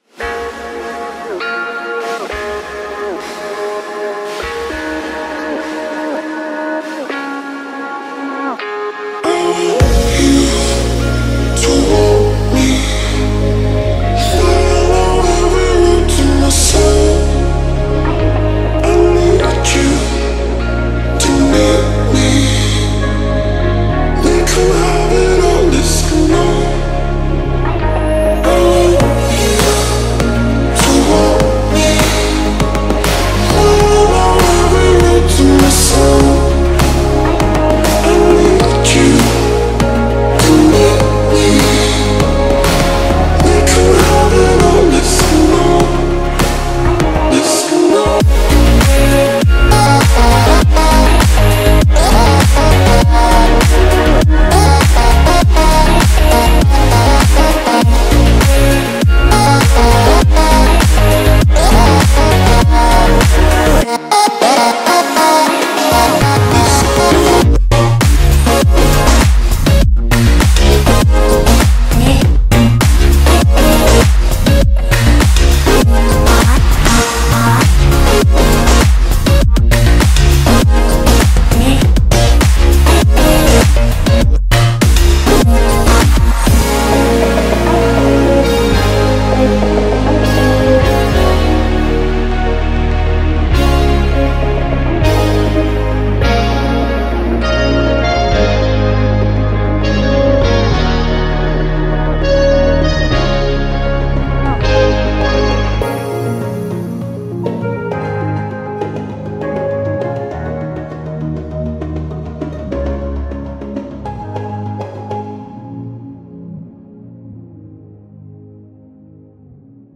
BPM100
Audio QualityPerfect (High Quality)
Comments[80s INDIE-ELECTRO]